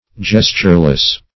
Gestureless \Ges"ture*less\, a.